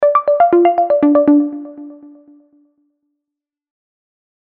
Scifi 7.mp3